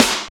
46.04 SNR.wav